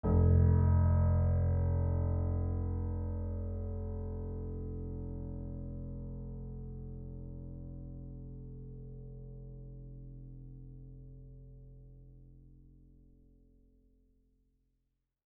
GreatAndSoftPiano